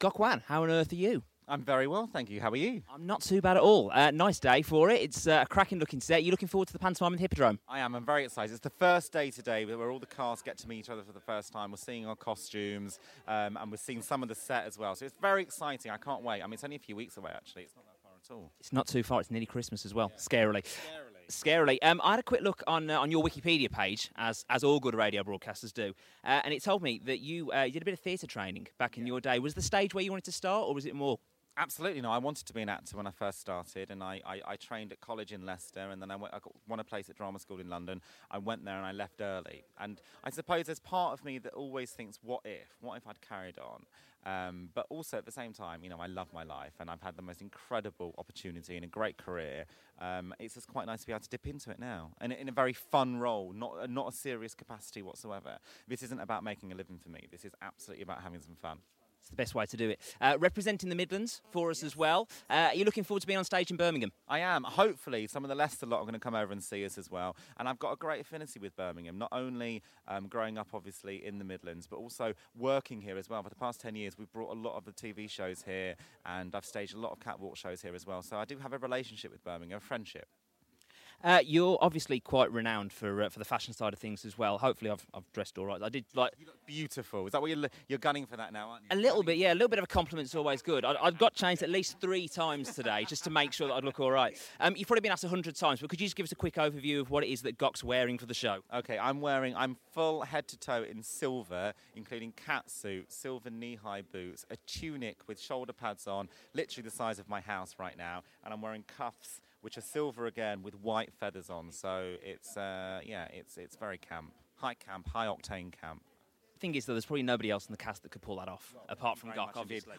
Gok Wan jumped at the chance to star in his first ever professional pantomime at Birmingham Hippodrome in 2013. I grabbed a few minutes with him to find out if he was nervous?